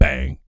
BS BANG 01.wav